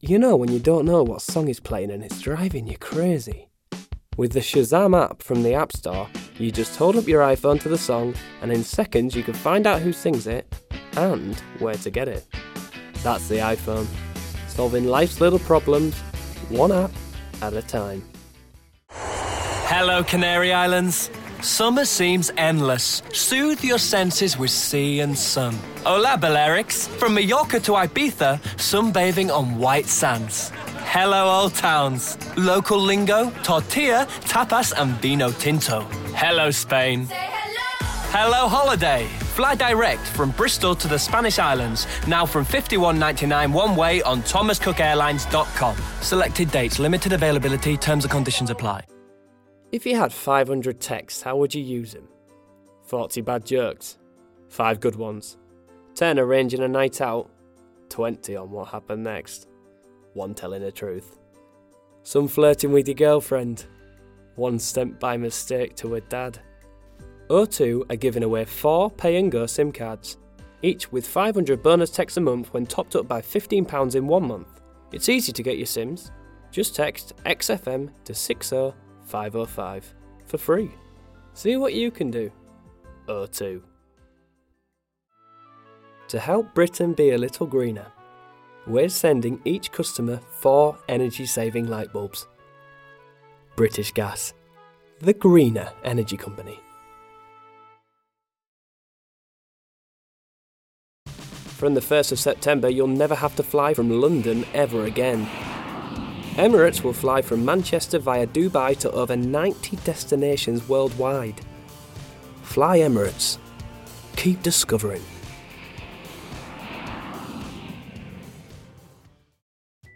Charming, Natural